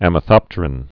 (ămə-thŏptər-ĭn)